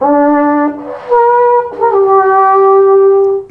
Sons cors des Alpes
SONS ET LOOPS DE CORS DES ALPES
Banque sons : INSTRUMENTS A VENT